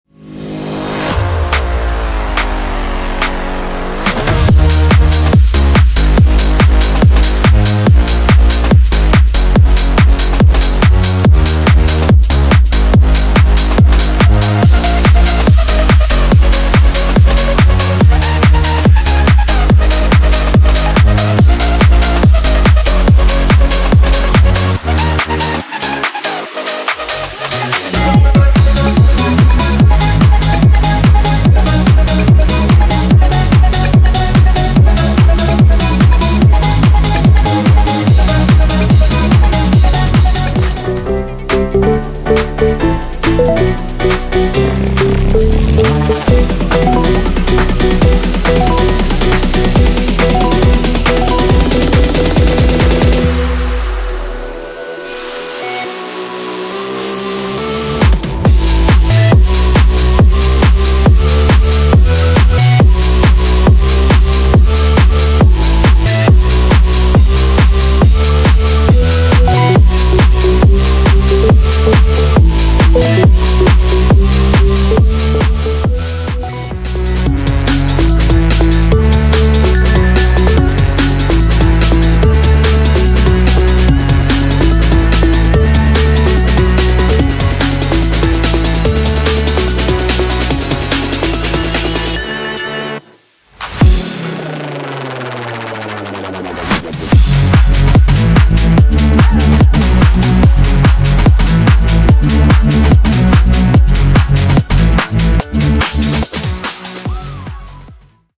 uk garage sounds for xfer serum 2 synthesizer
• Gritty UK basses with proper movement and mid-range growl
• Spicy chords and house pianos for rapid sketches
MP3 DEMO